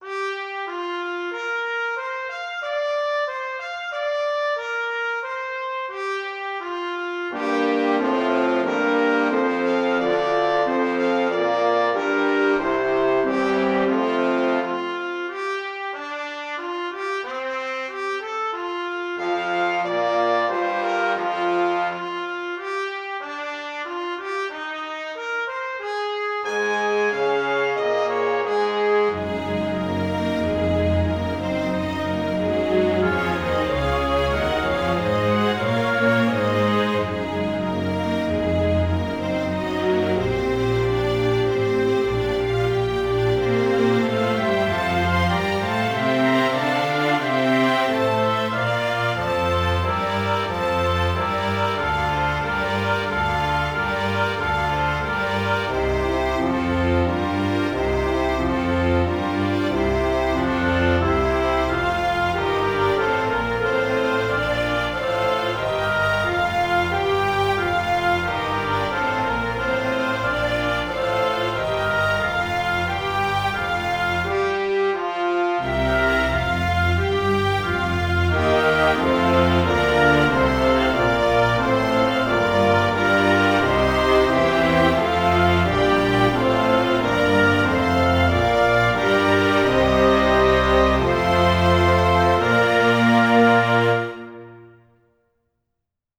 Finally there's an excerpt from Promenade from Pictures at an Exhibition by Mussorgsky, arranged for an orchestra.
No processing of any kind, all the reverb is present in the samples.